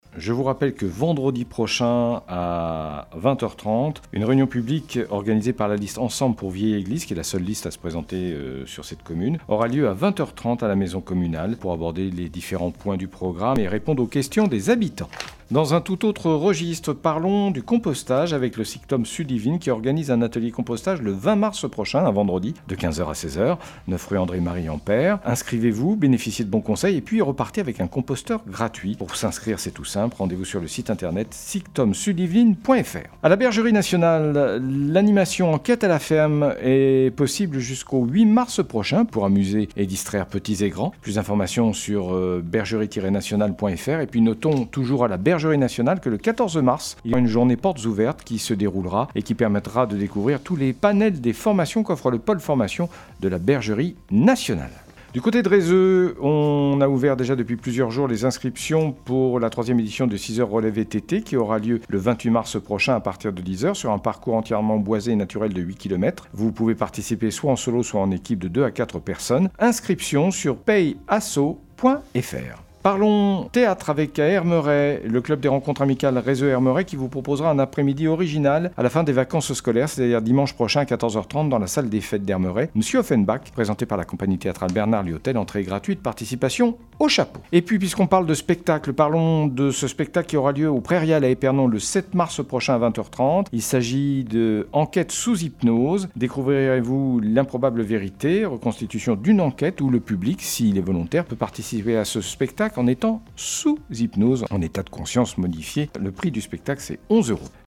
02.03-FLASH-LOCAL-MATIN.mp3